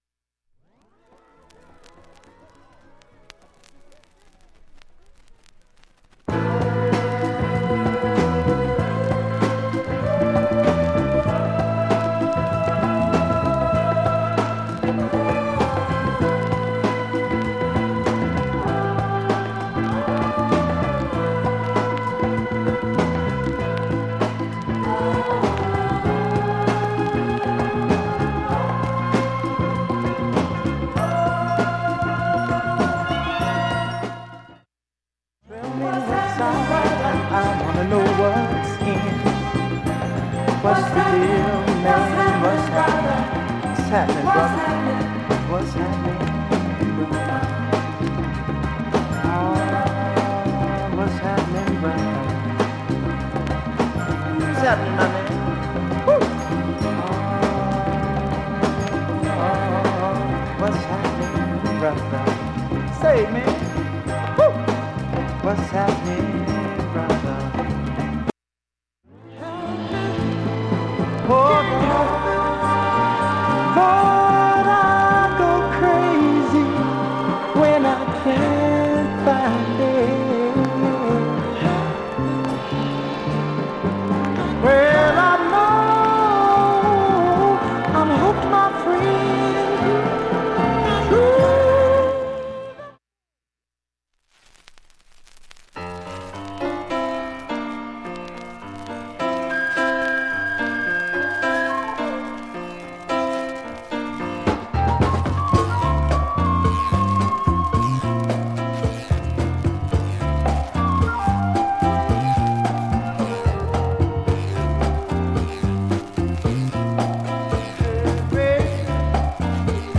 目立ってプツなどもありません。
３回までのかすかなプツが４箇所
単発のかすかなプツが８箇所